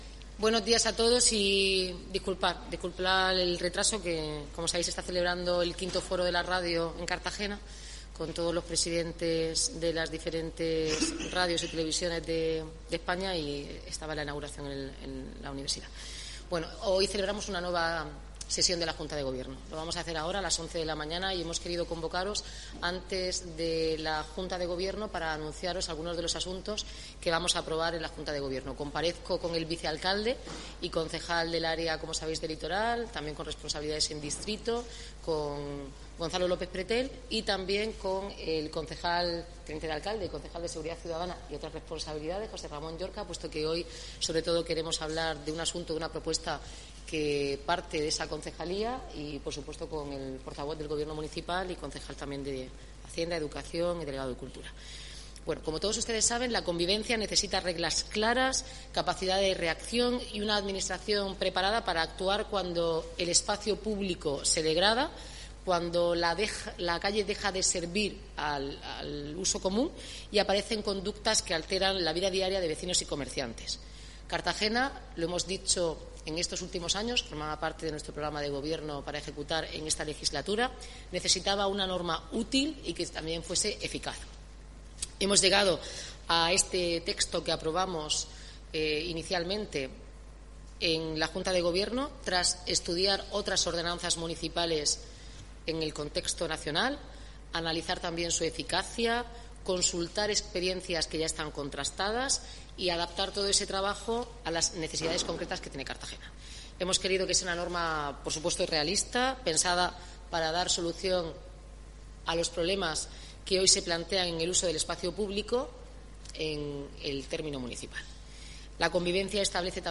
Audio: Declaraciones alcaldesa sobre la nueva ordenanza de convivencia (MP3 - 11,24 MB)